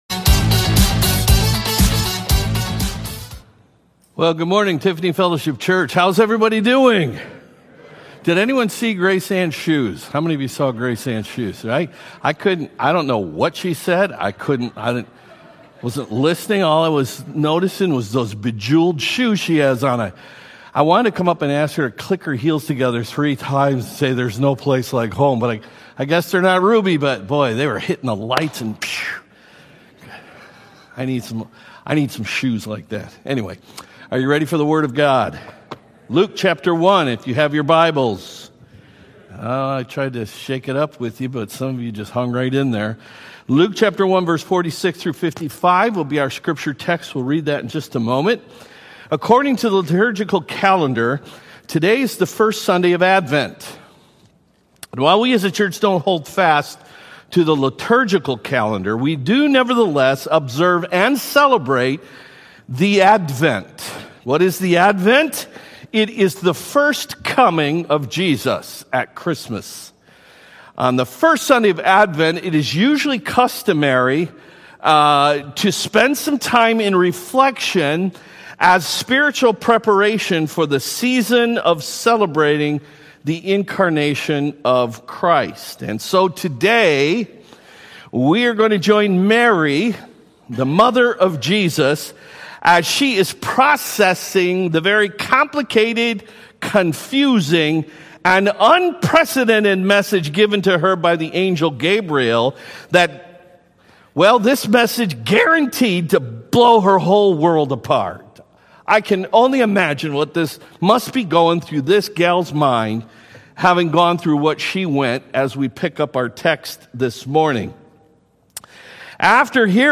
Sermons & Media – Page 55 – Tiffany Fellowship Church | Kansas City